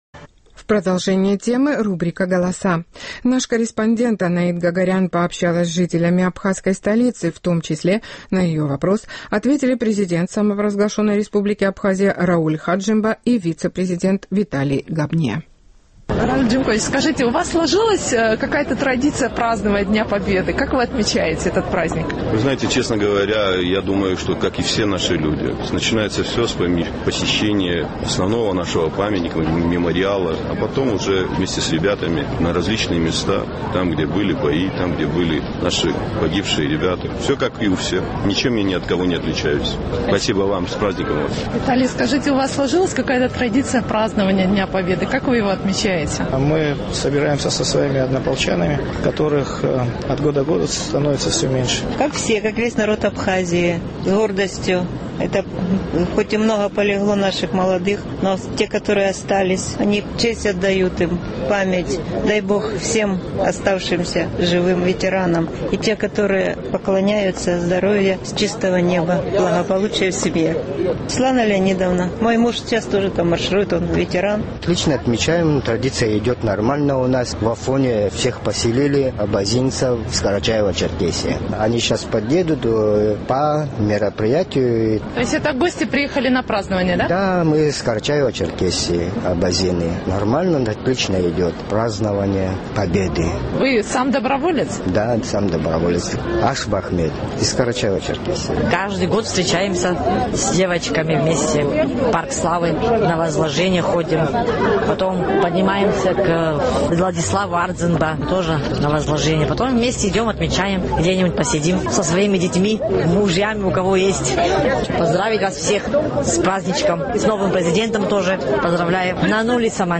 Сегодня в Абхазии празднуют День независимости самопровозглашенной республики. Наш сухумский корреспондент пообщалась с жителями абхазской столицы, в том числе с главой самопровозглашенной республики и вице-президентом.